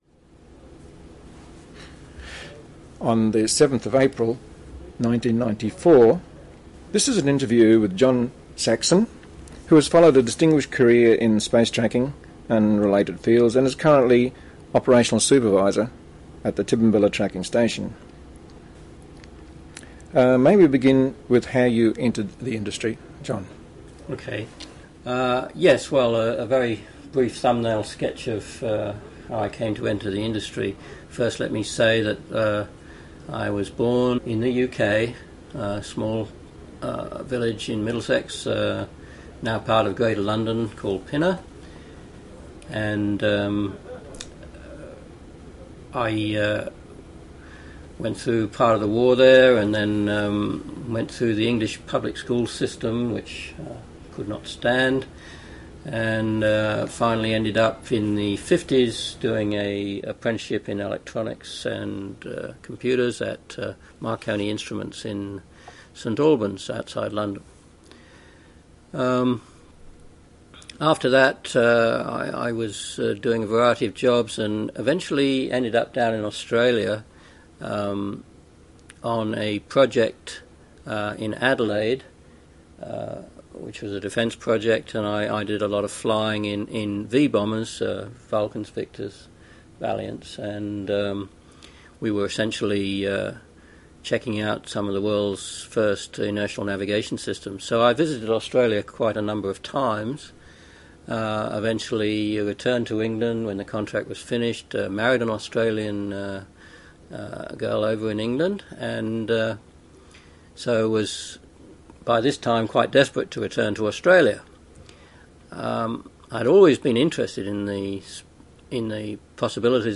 interview 1994